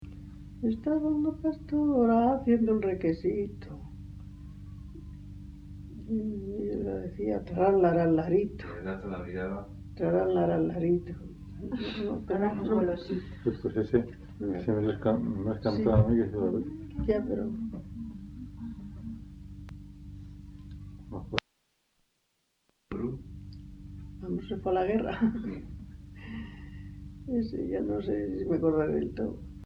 Grabación realizada en La Overuela (Valladolid), en 1977.